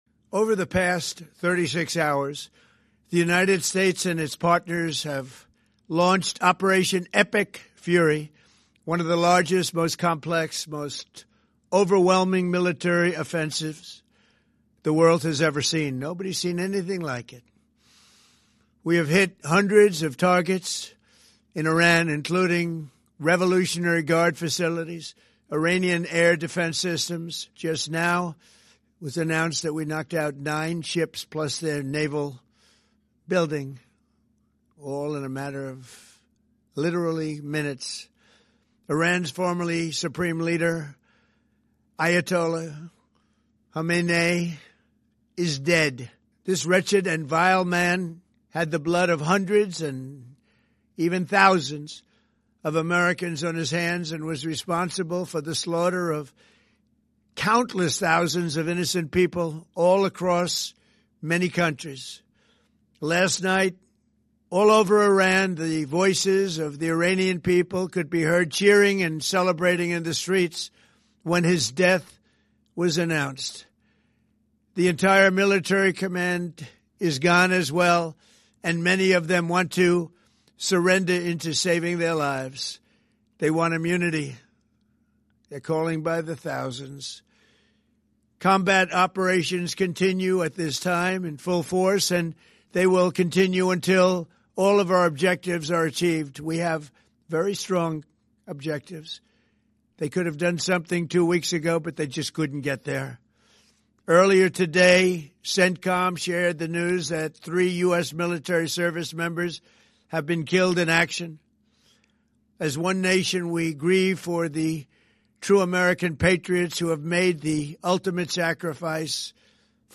gesamte 6 Minuten Rede übersetzt.